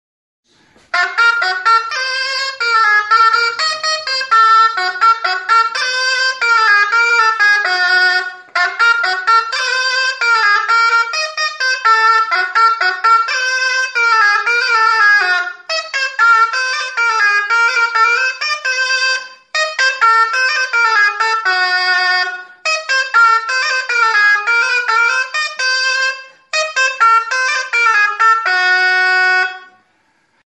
Music instrumentsDULZAINA
Aerophones -> Reeds -> Double (oboe)
Recorded with this music instrument.